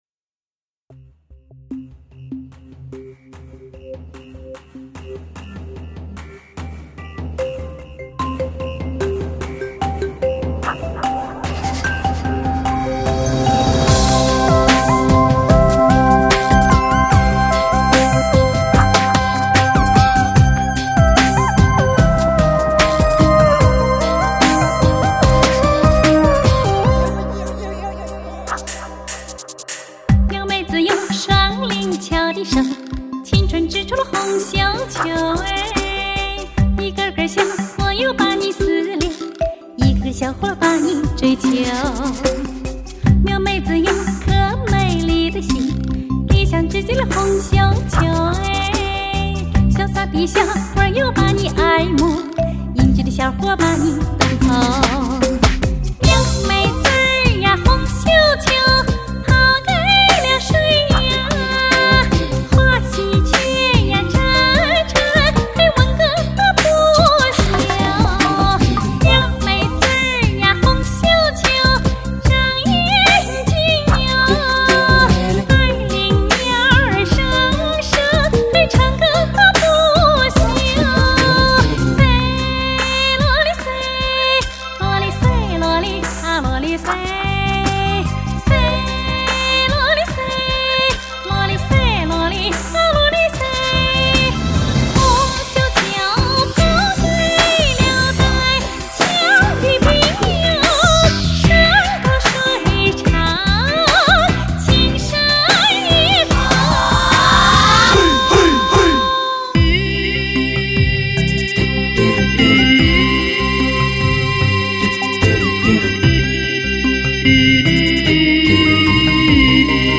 配器使用了大量弦乐， 整张专辑音乐宏大，气势磅礴，人声清亮。